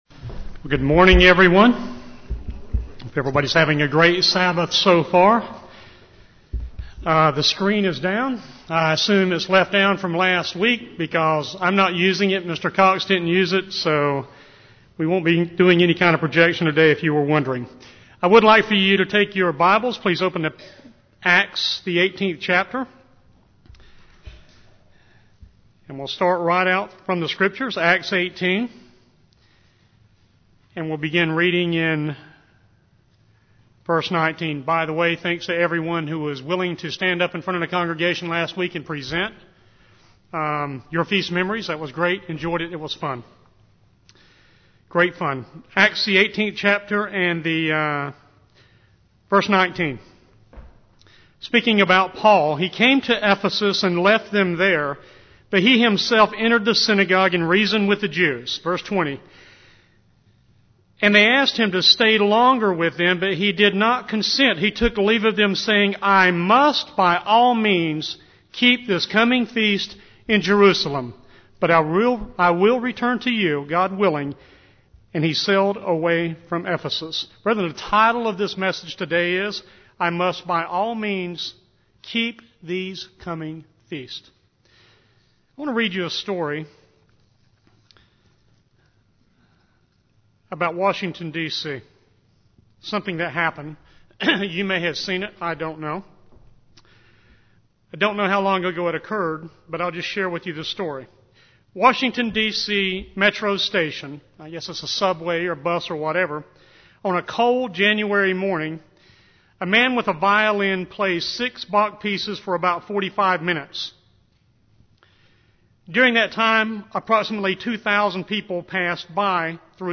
Given in Raleigh, NC
Print Spiritual gifts received from God at His Feasts and a look at the spiritual benefits gained during the Feast of Tabernacles UCG Sermon Studying the bible?